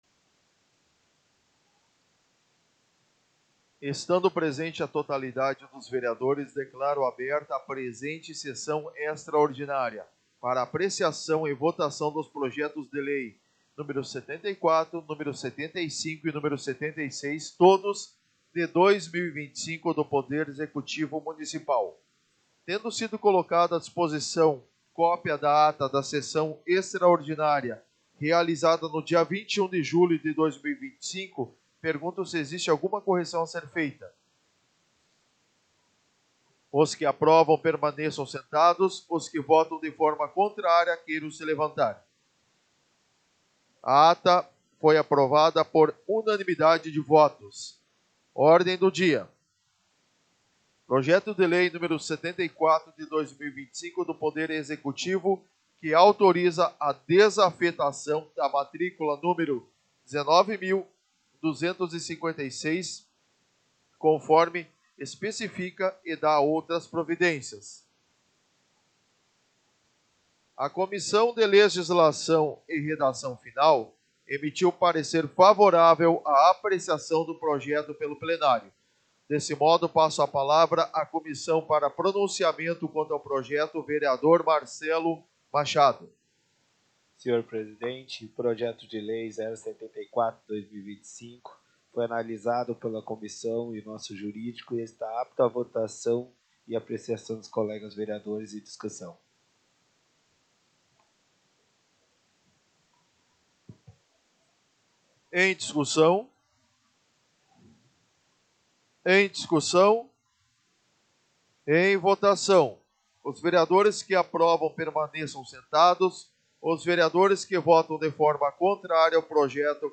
Áudio Sessão Extraordinária 11.08.2025